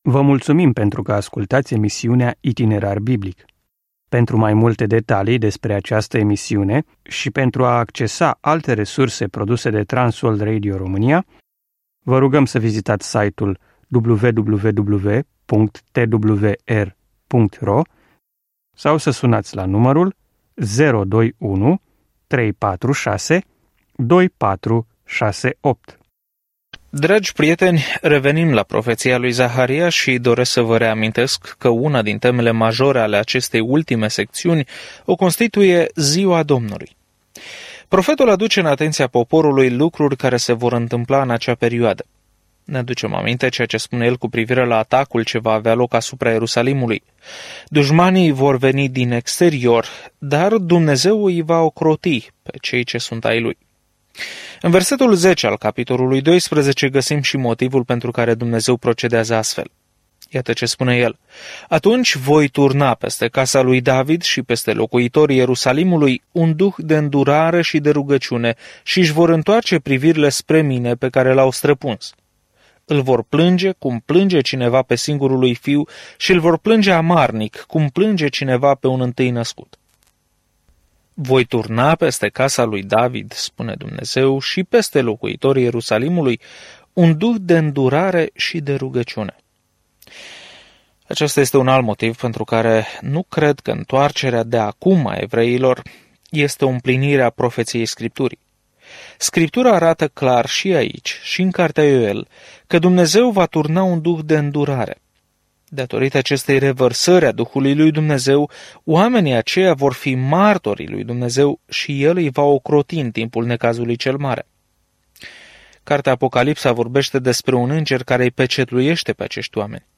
Scriptura Zaharia 12:10-14 Ziua 18 Începe acest plan Ziua 20 Despre acest plan Profetul Zaharia împărtășește viziuni despre promisiunile lui Dumnezeu de a oferi oamenilor o speranță în viitor și îi îndeamnă să se întoarcă la Dumnezeu. Călătoriți zilnic prin Zaharia în timp ce ascultați studiul audio și citiți versete selectate din Cuvântul lui Dumnezeu.